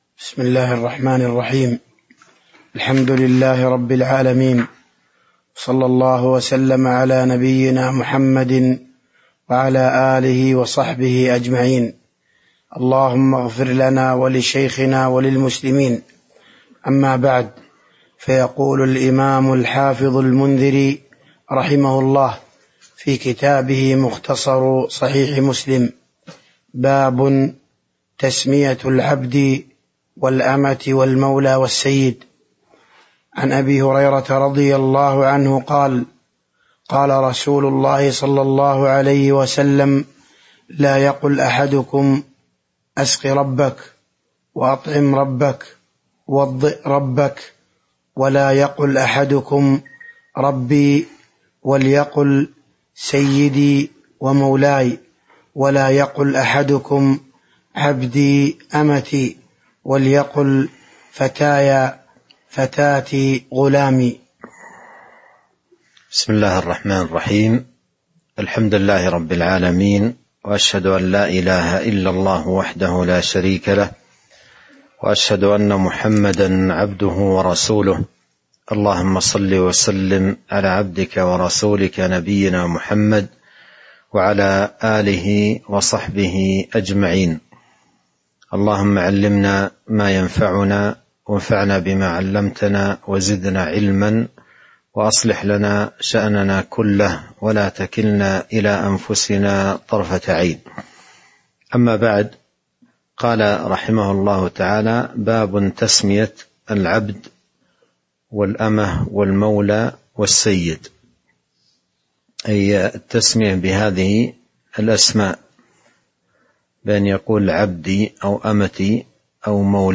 تاريخ النشر ١٠ رجب ١٤٤٣ هـ المكان: المسجد النبوي الشيخ